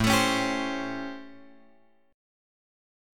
A7#9 chord {5 4 5 5 x x} chord